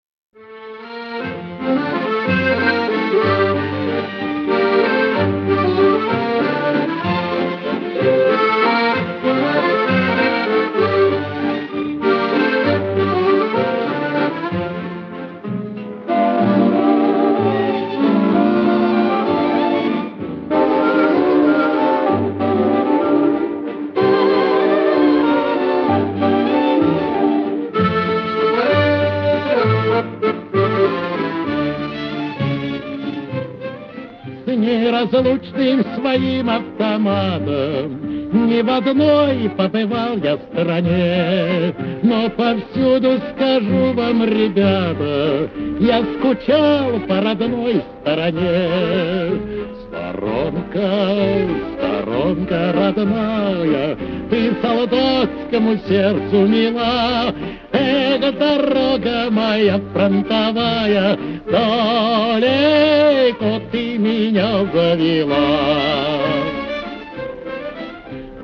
Фрагмент песни